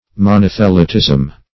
Monothelism \Mo*noth"e*lism\, Monothelitism \Mo*noth"e*li*tism\,